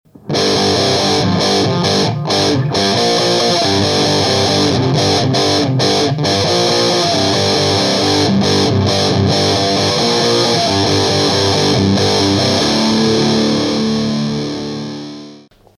Sweep02-147K.mp3